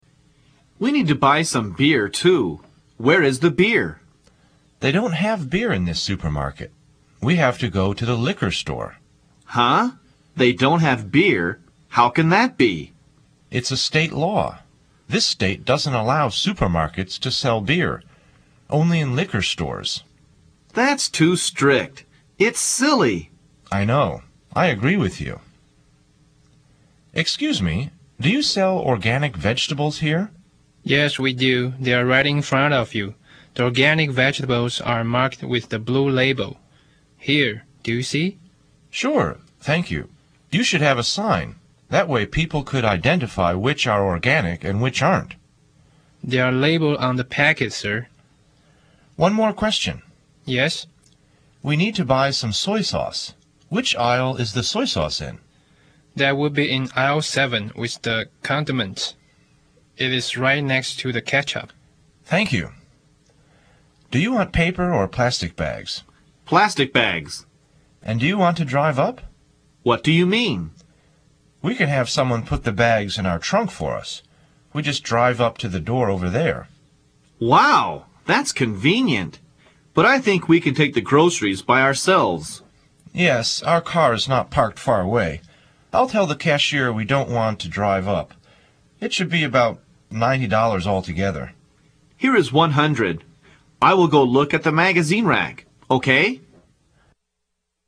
购物英语对话 第6讲:超市